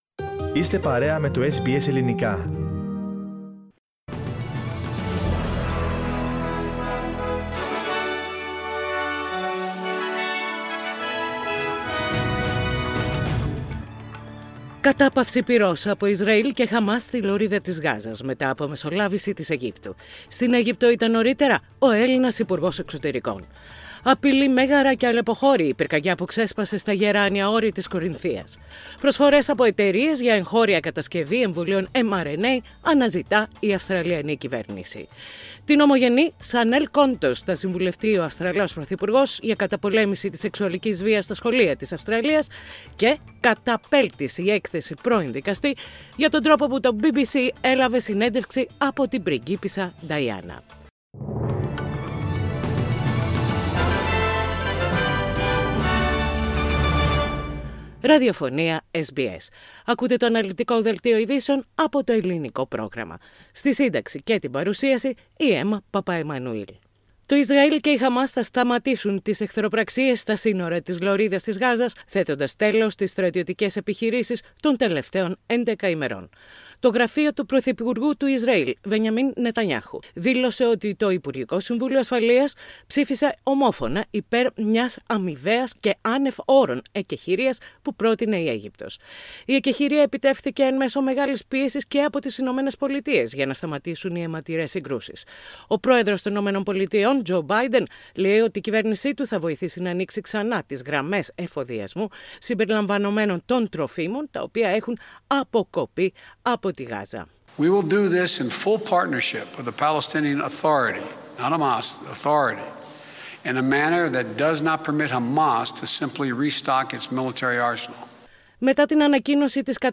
Ειδήσεις στα Ελληνικά - Παρασκευή 21.5.21
Οι κυριότερες ειδήσεις της ημέρας, από το Ελληνικό πρόγραμμα της ραδιοφωνίας SBS.